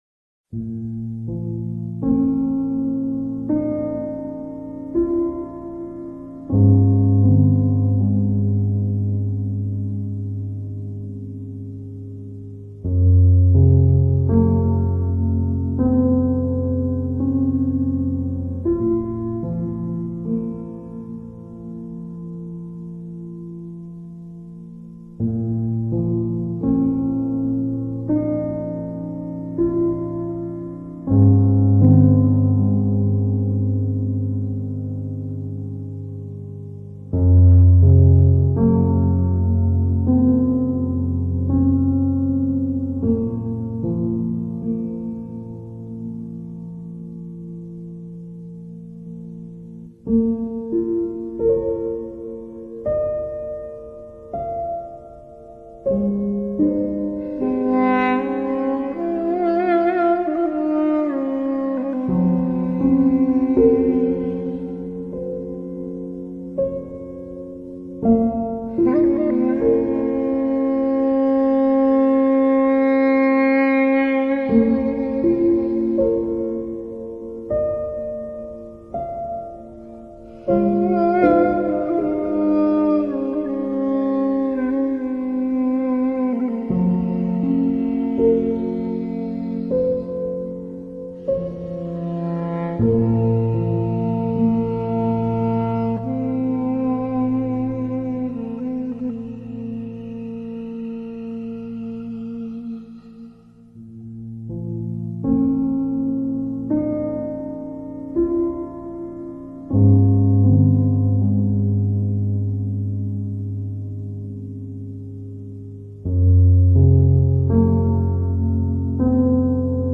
(Slowed+Reverb)